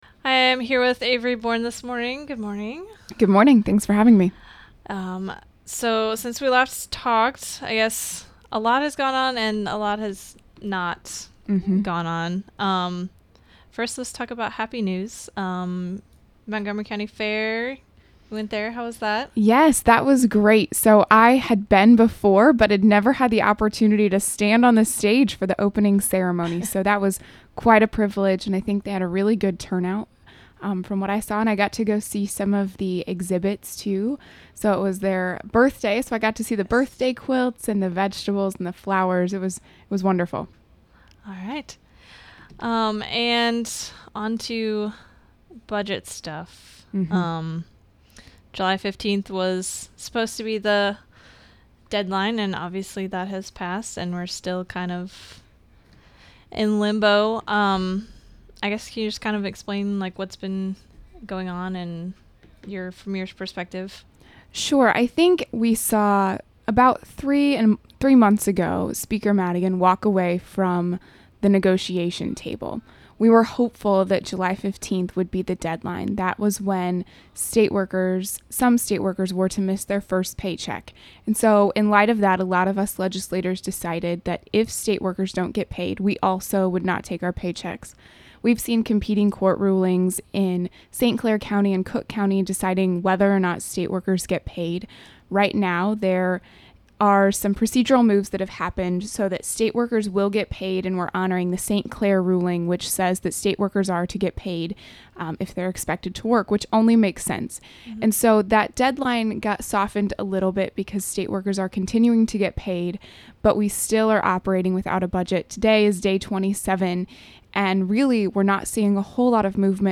07/27/2015 July Interview with State Representative Avery Bourne. She discusses state budget sessions, upcoming events and advisory board meetings.